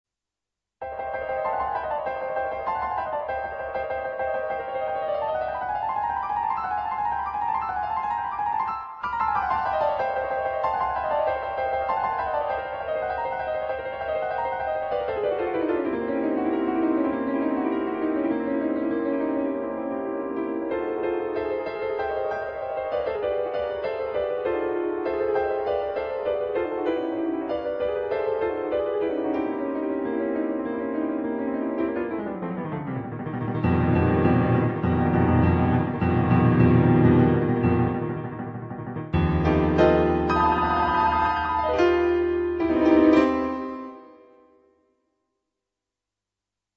on Yamaha digital pianos.